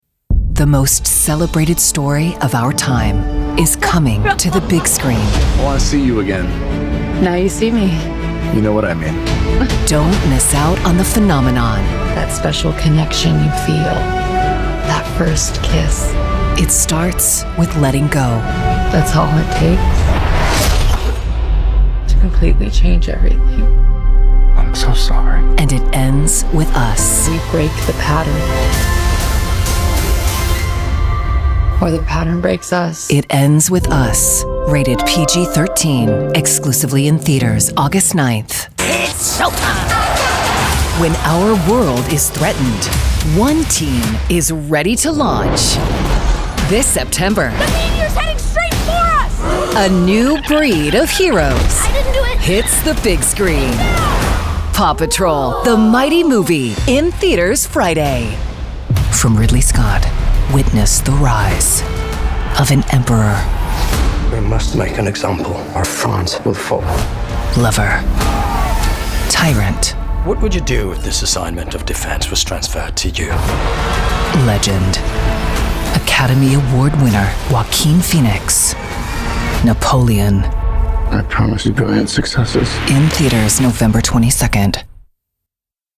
All of our contracted Talent have broadcast quality home recording studios.
Trailer Demo Video Demos It Ends With Us Back to Voiceover Talents